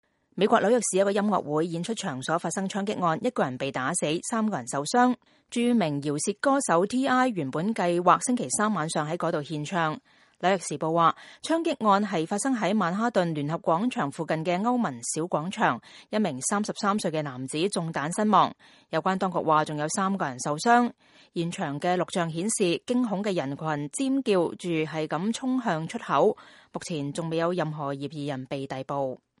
現場錄像顯示，驚恐的人群尖叫著衝向出口。